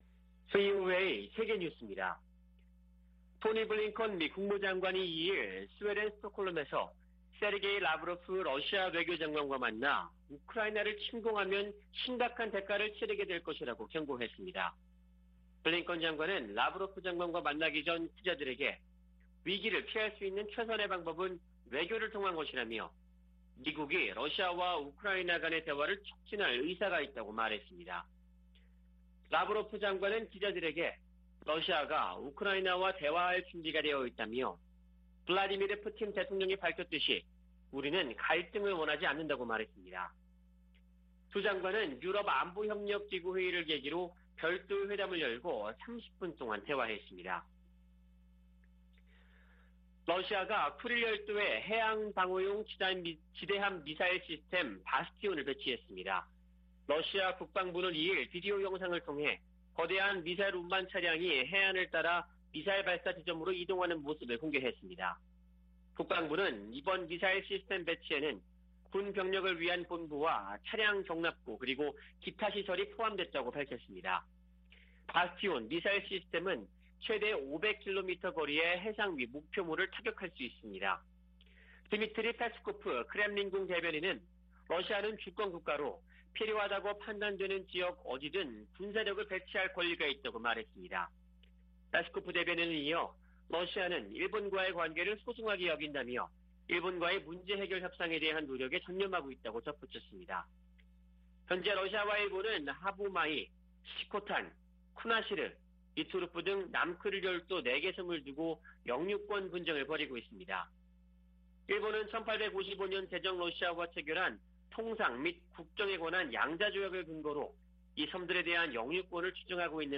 VOA 한국어 아침 뉴스 프로그램 '워싱턴 뉴스 광장' 2021년 12월 3일 방송입니다. 미-한 두 나라 국방 장관은 서울에서 양국 안보협의회를 마치고 북한의 핵과 미사일 능력에 대응하는 새로운 작전 계획 수립을 위해 새로운 기획지침을 승인했다고 밝혔습니다. 미국민 78%가 북한을 적국으로 인식한다는 여론조사 결과가 나왔습니다.